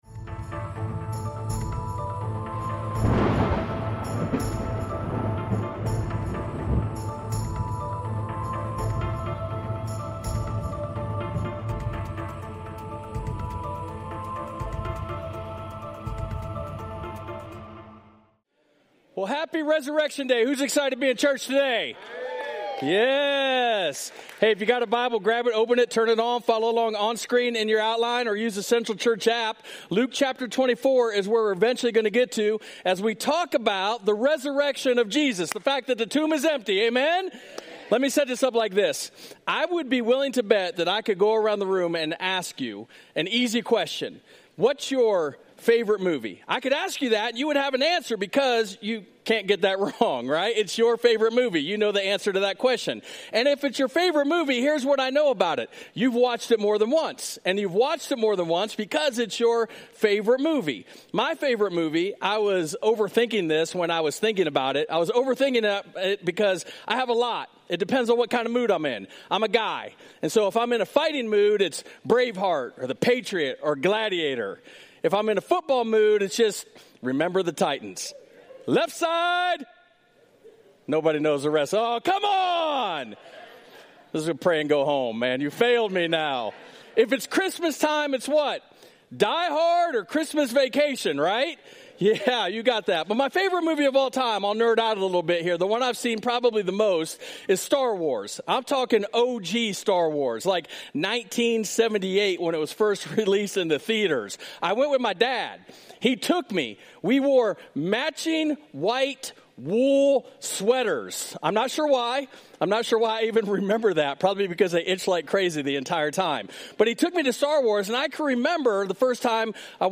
We're excited to share this special Easter message.